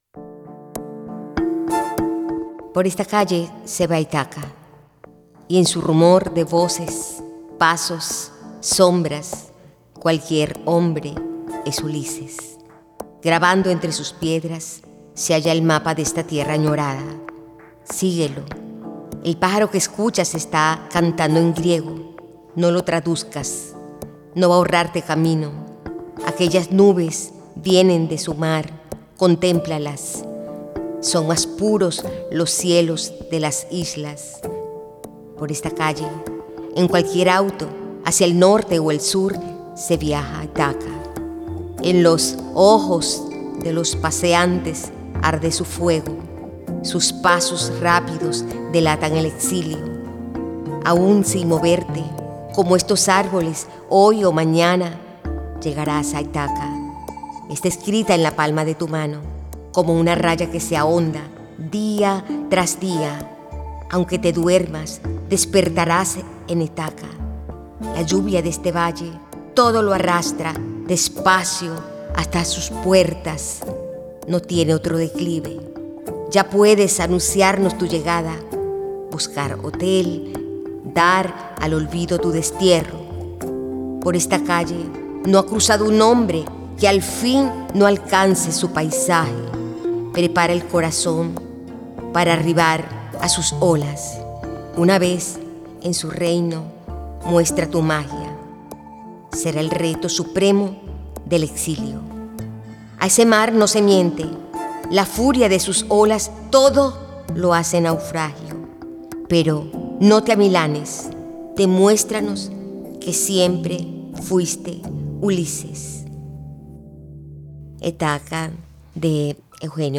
Aula de Letras al Aire es un programa de radio universitaria que lleva la literatura (poemas y relatos) más allá del aula, ofreciendo una experiencia auditiva y accesible para la comunidad universitaria y el público en general.